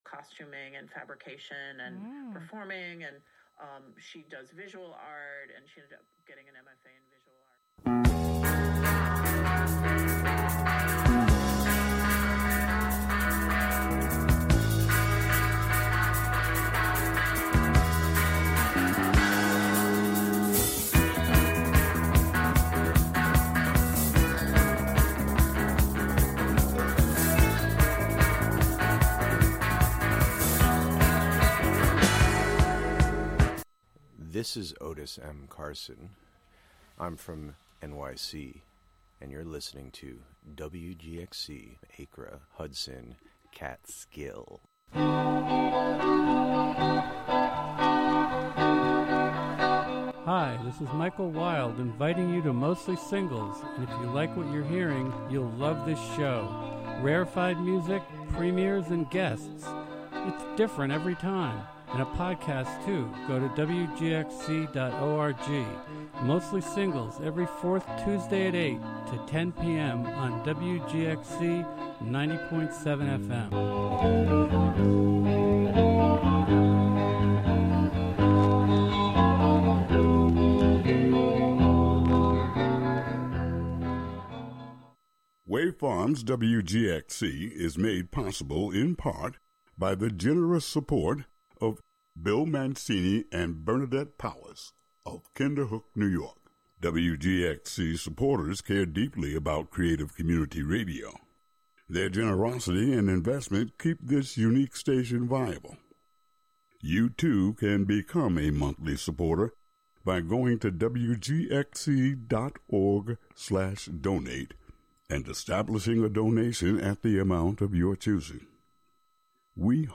Get yr weekly dose of music appreciation, wordsmithing, and community journalism filtered through the minds and voices of the Youth Clubhouses of Columbia-Greene, broadcasting live out of the Catskill Clubhouse on Fridays at 6 p.m. and rebroadcast Sundays at 7 a.m.